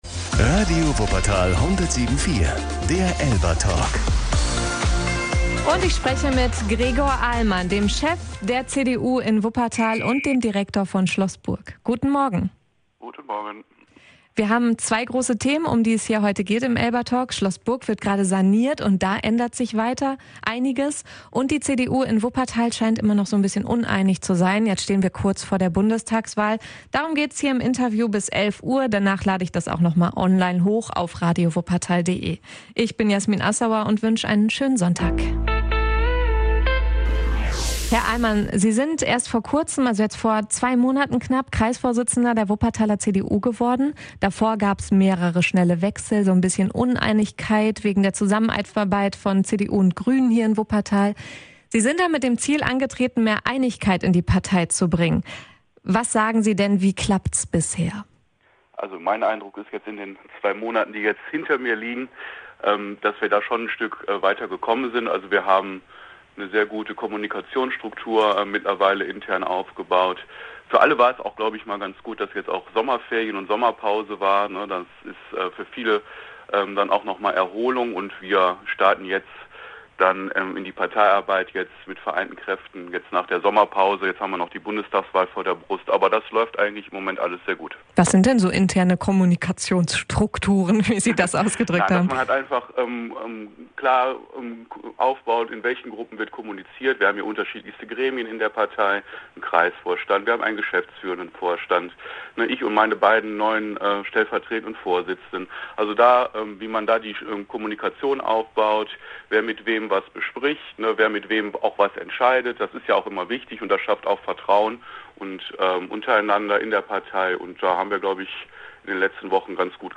Im zweiten Teil des Interviews geht es um Schloss Burg.